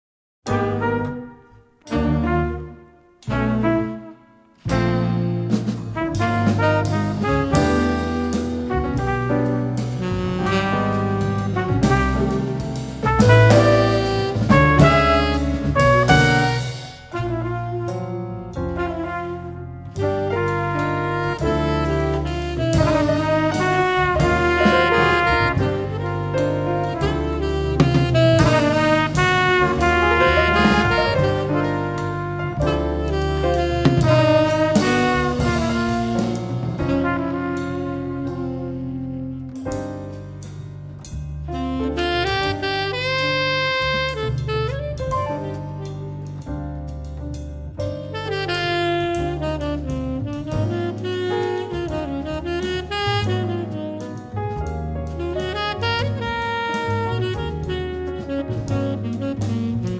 batteria
sassofoni e flauto (1, 2, 3, 4, 7, 8, 10, 11)